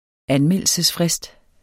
Udtale [ ˈanˌmεlˀəlsəs- ]